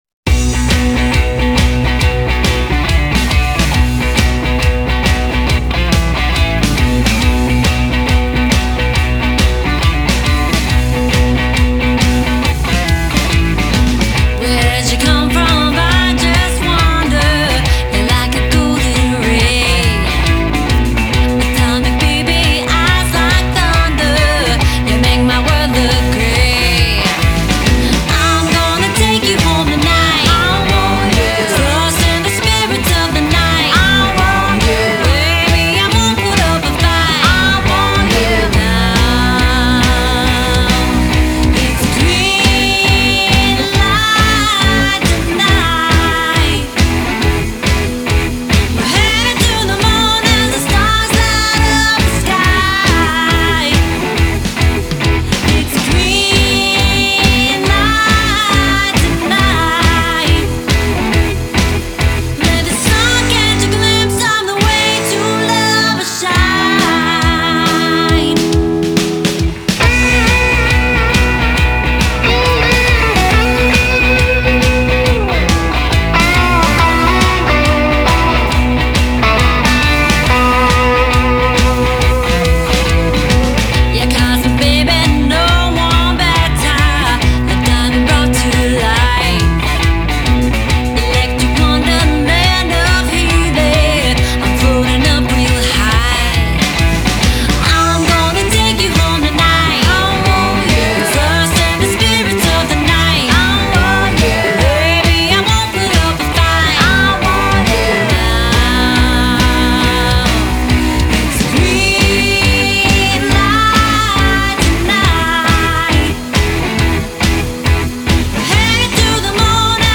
Killer Vocals, Killer Guitar, Killer Rock !!
Genre: Blues, Blues Rock